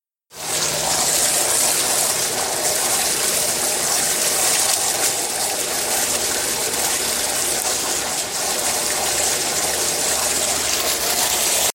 描述：浇水和溅水
标签： 场记录 喷泉 飞溅
声道立体声